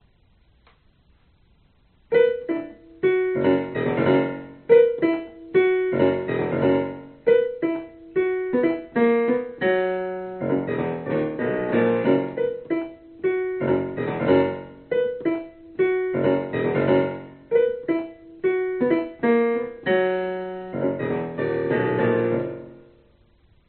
钢琴 器乐 A节 Bebop